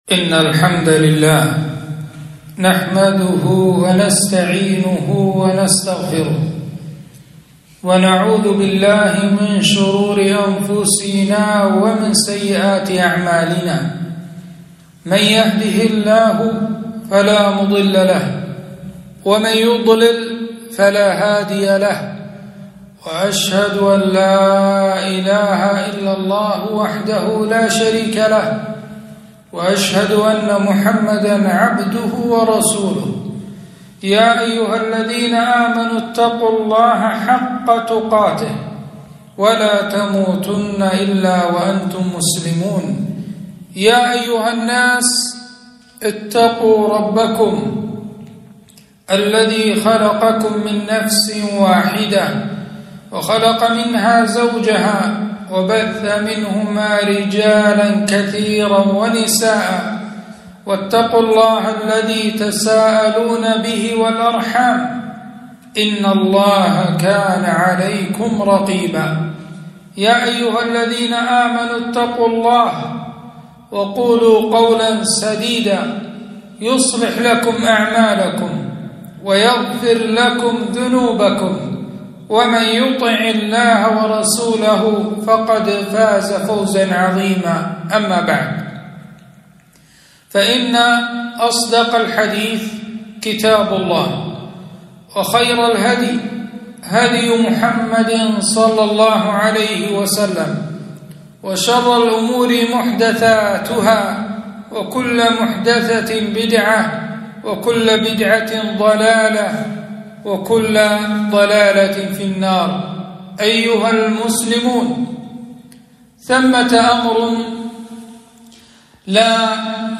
خطبة - الوقف الإسلامي مجالاته وأبعاده